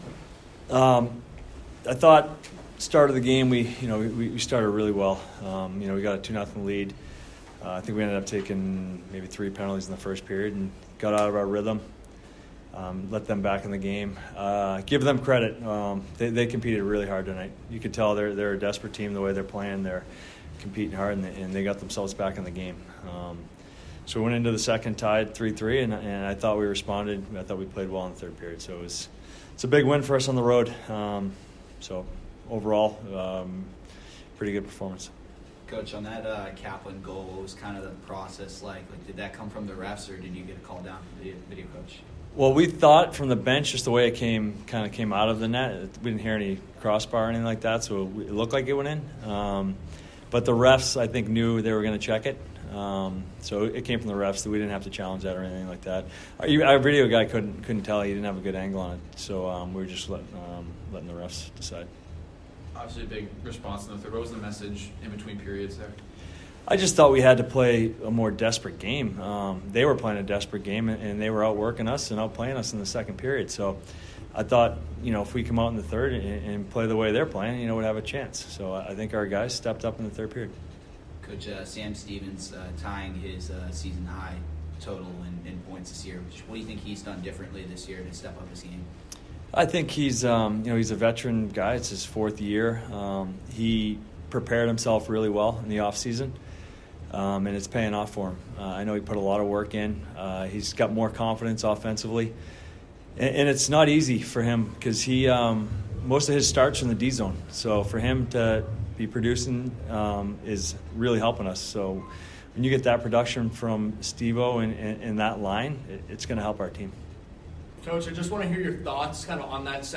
Coach Pandolfo - Men's Ice Hockey / New Hampshire Postgame Interview (12-2-22)
December 02, 2022 Boston University men's ice hockey head coach Jay Pandolfo addresses the media after the Terriers' 6-3 win at New Hampshire on Dec. 2, 2022.